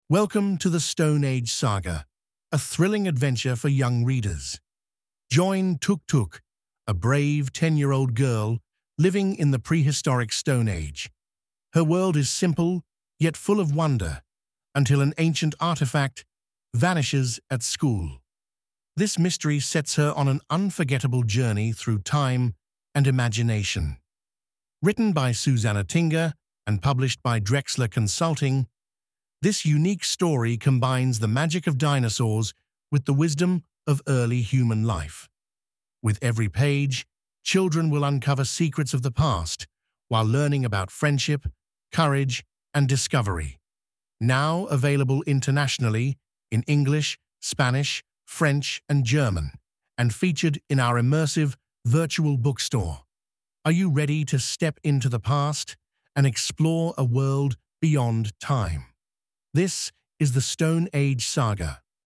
-Audio Preview-